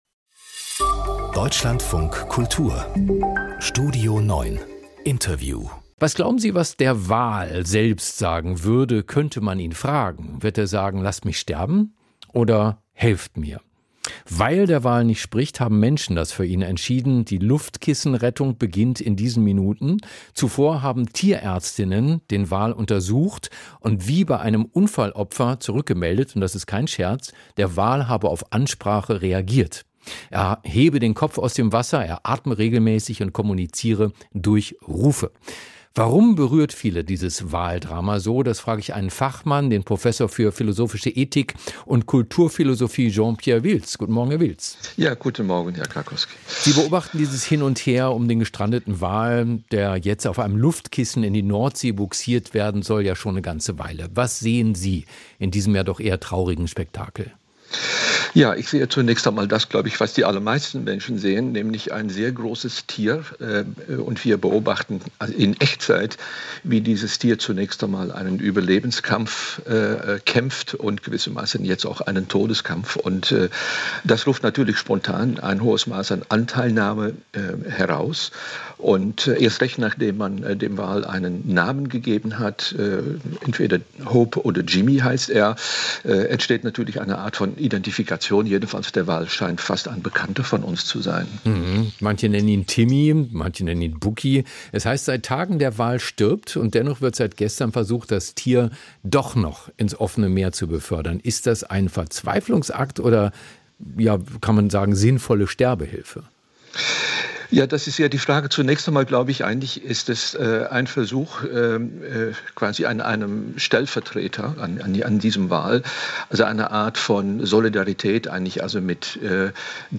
Podcast: Interview